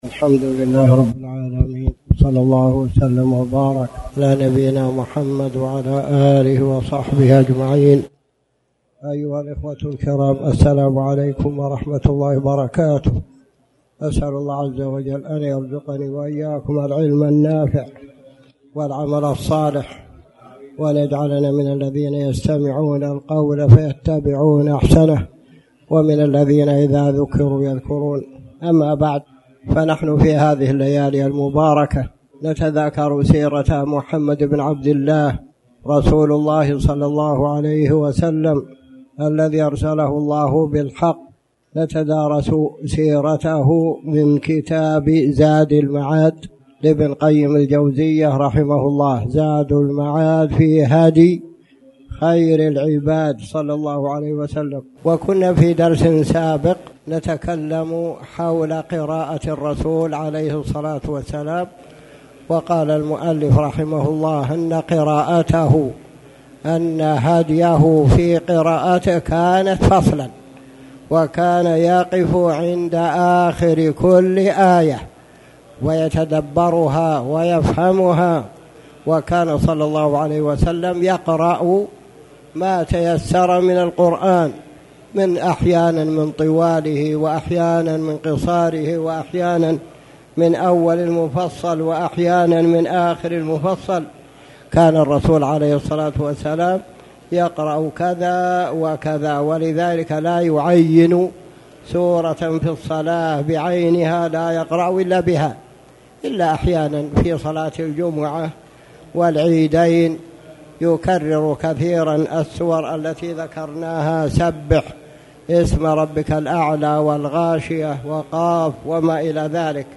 تاريخ النشر ٢١ رجب ١٤٣٩ هـ المكان: المسجد الحرام الشيخ